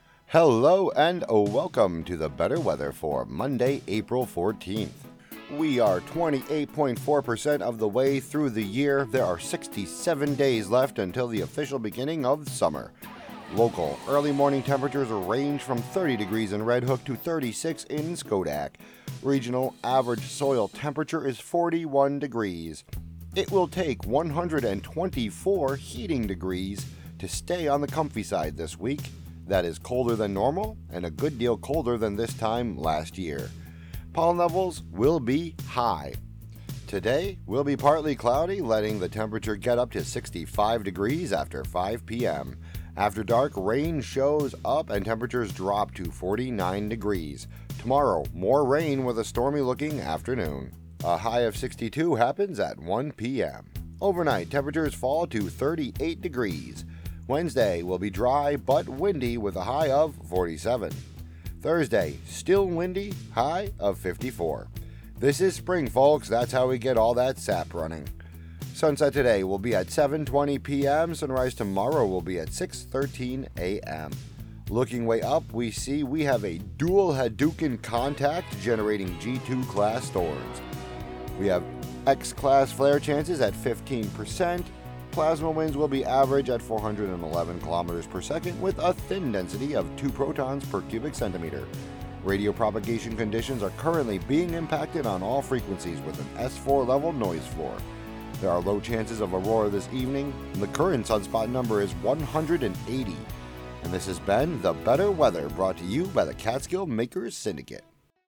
brings listeners meteorological predictions, pollen counts, Hudson River water temperatures, space weather, and more on WGXC 90.7-FM.